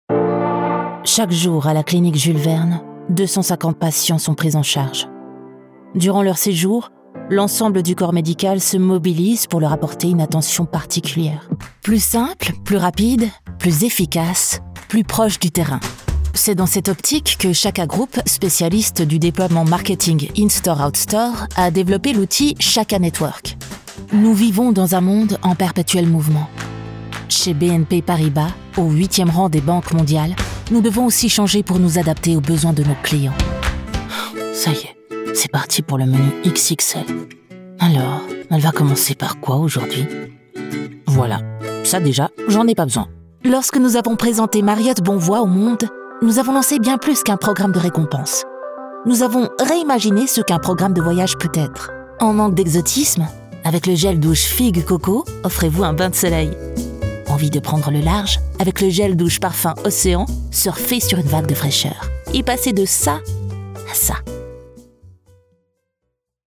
Commercial Demo
Microphone: NT1 RODE
Mezzo-SopranoSoprano
SoftSoothingSophisticatedPersonableEngagingIntelligentSensitiveGentleEnergeticGenuineSensualConfidentAuthentic
All our voice actors record in their professional broadcast-quality home studios using high-end microphones.